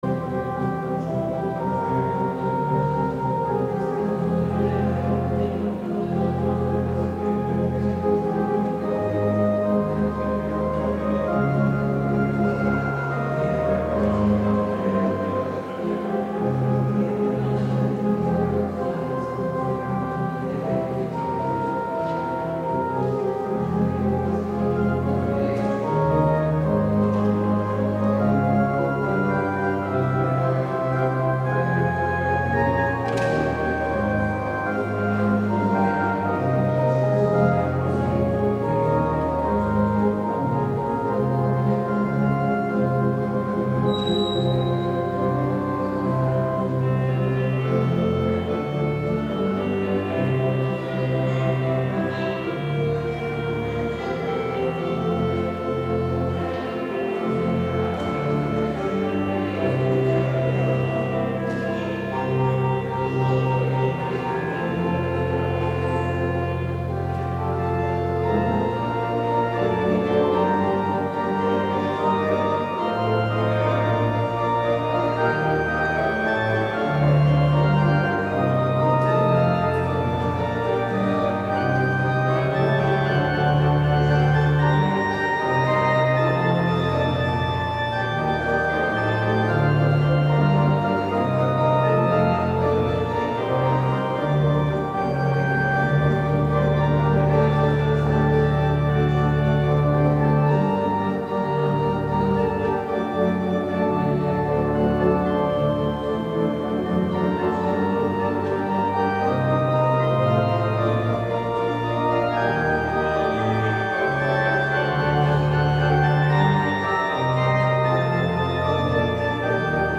Kerkdienst gemist